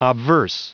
Prononciation du mot obverse en anglais (fichier audio)